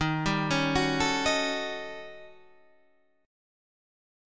D#7#9b5 Chord
Listen to D#7#9b5 strummed